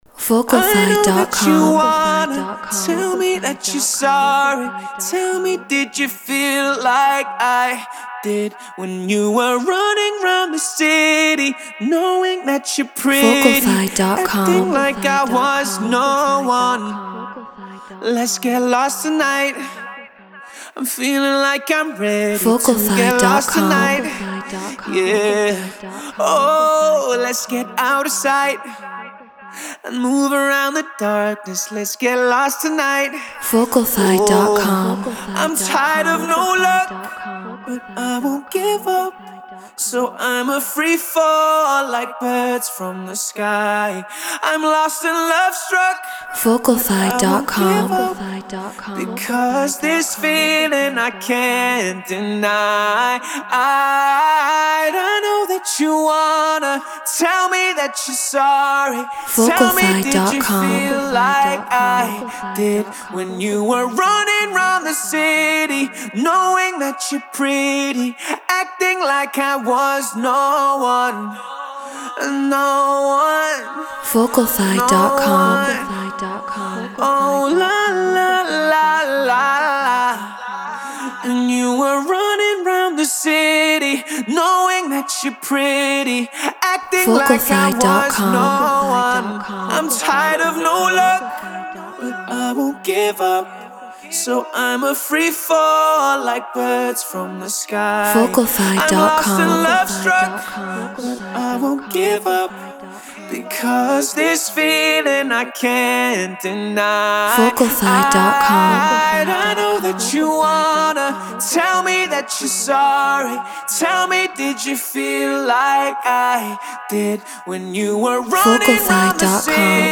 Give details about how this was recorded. High Quality WAV. Non-Exclusive Vocal.